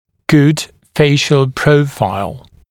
[gud ‘feɪʃ(ə)l ‘prəufaɪl][гуд ‘фэйш(э)л ‘проуфайл]хороший профиль лица